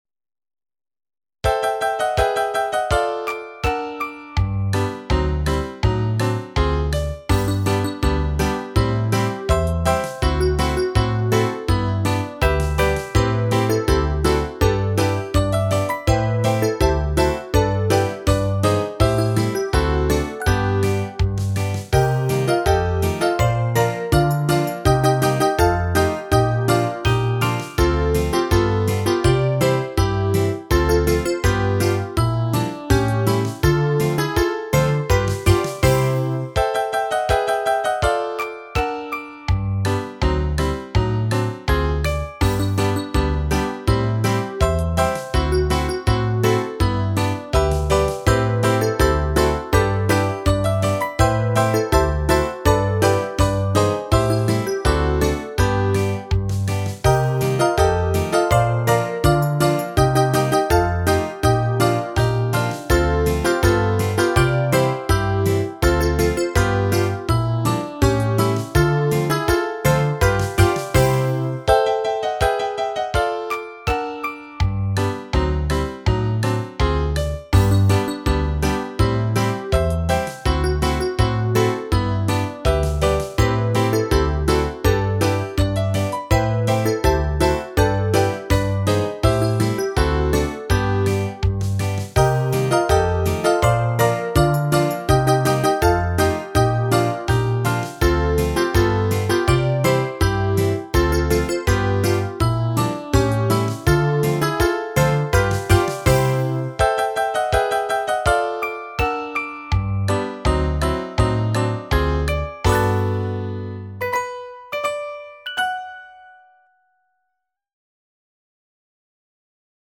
ГлавнаяПесниПесни про лето